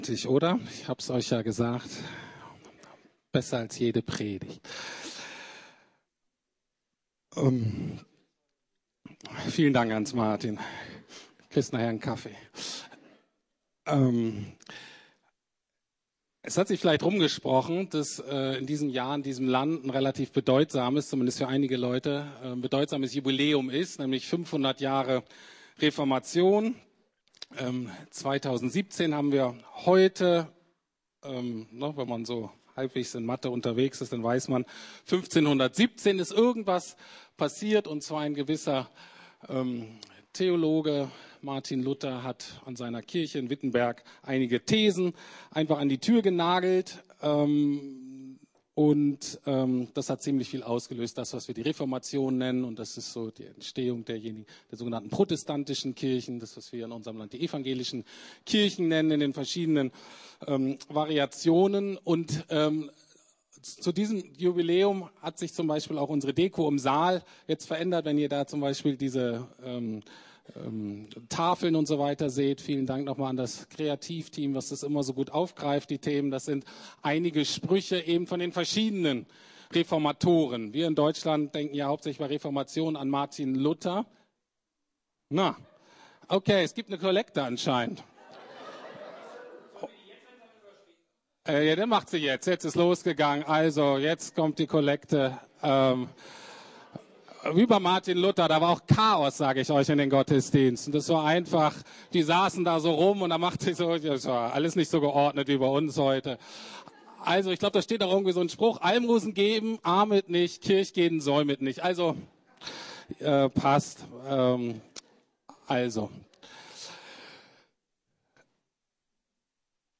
Predigt von Martin Luther (13. April 1533)